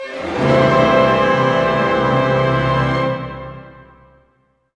Index of /90_sSampleCDs/Roland - String Master Series/ORC_Orch Gliss/ORC_Major Gliss